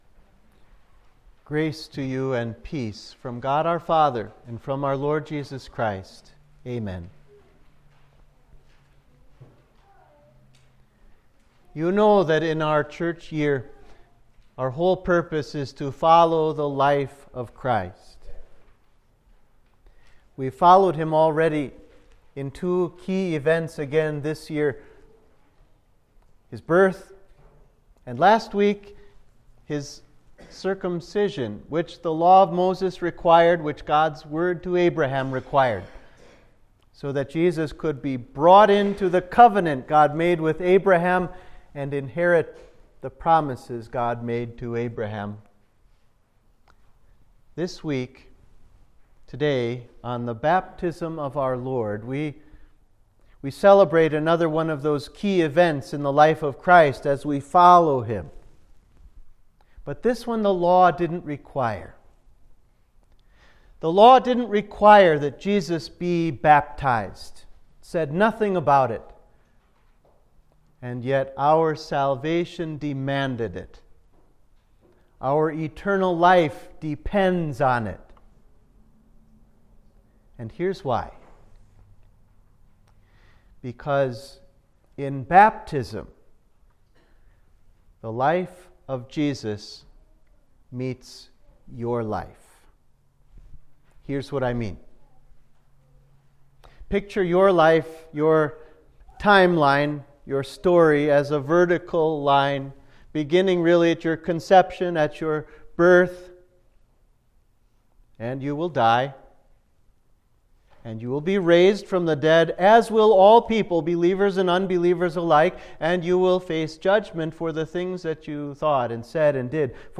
Sermon for Baptism of Our Lord